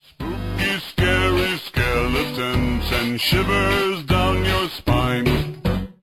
spooky.ogg